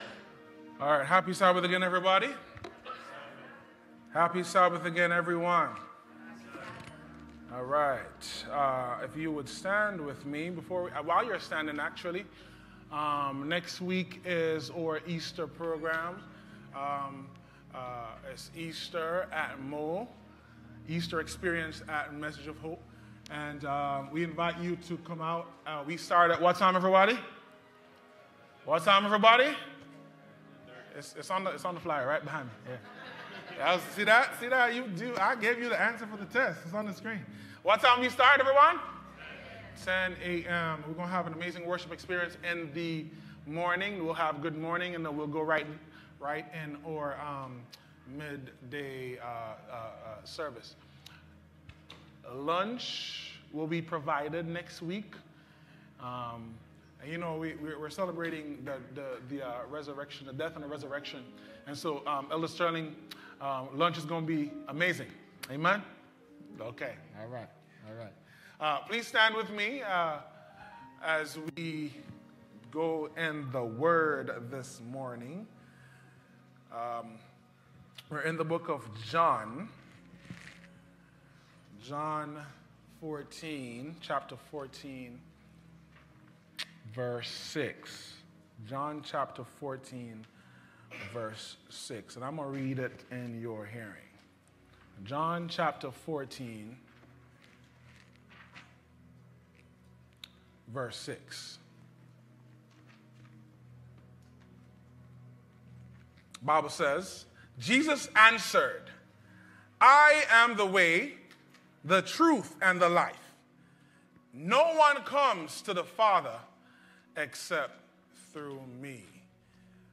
Sermons | Message of Hope SDA Community Mission